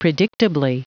Prononciation du mot predictably en anglais (fichier audio)
predictably.wav